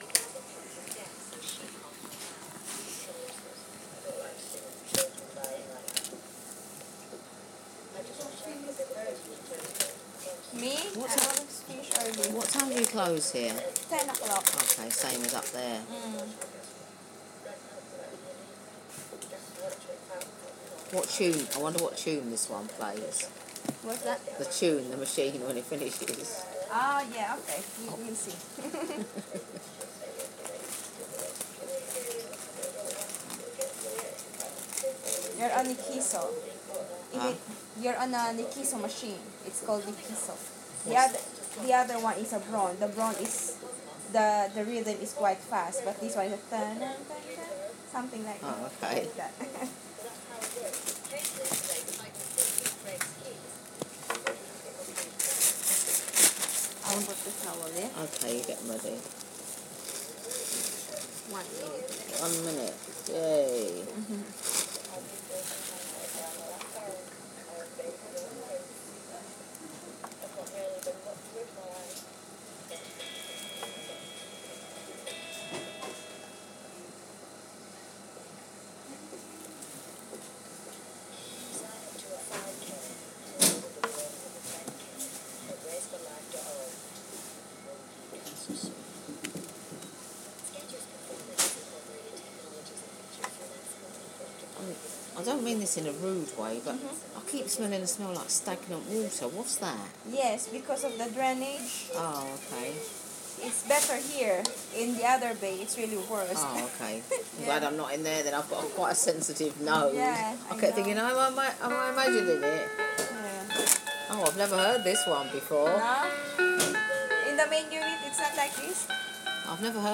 What tune does this dialysis machine play?